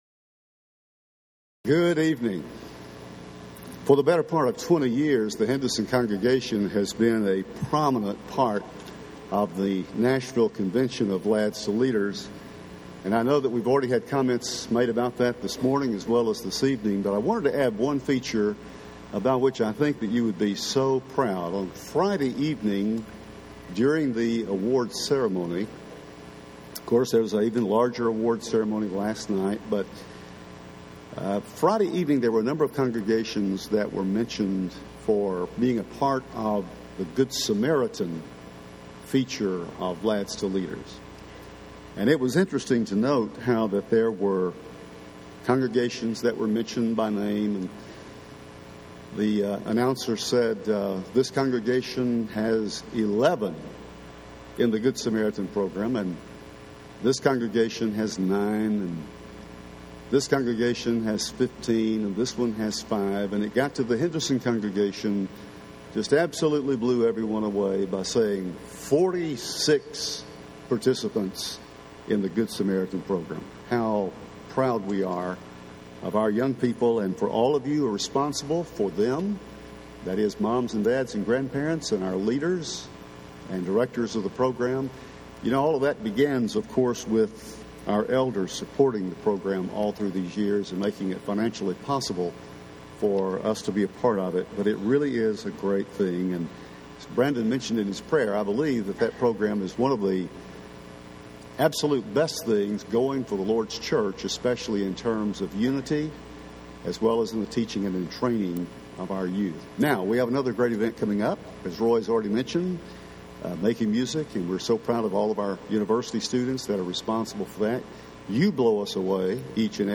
This evening’s lesson is from our daily Bible reading for today, the familiar story of God’s appearance to Moses in the burning bush. When God told him to return to Egypt to lead the children of Israel out of captivity, Moses gave a series of excuses that are exactly those we use today.